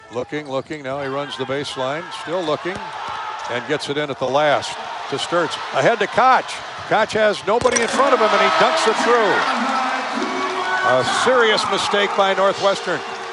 nwcall1.mp3